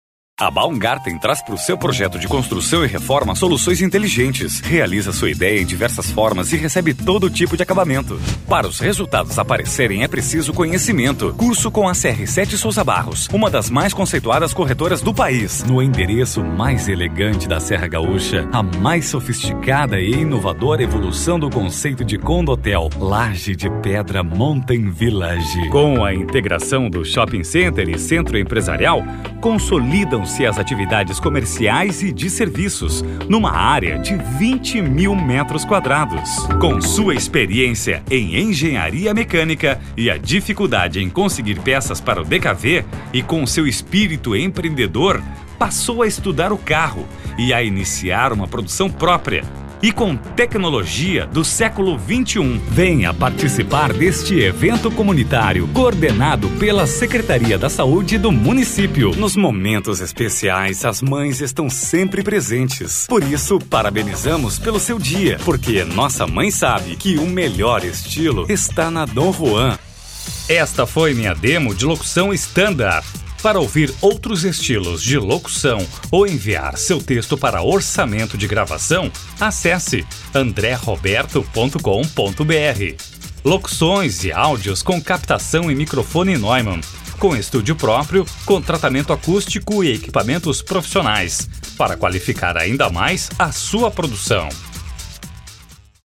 Professional announcer for more than 20 years, with his own studio and professional equipment, using microphone Neumann TLM 103, the guarantee of a great work.
Sprechprobe: Industrie (Muttersprache):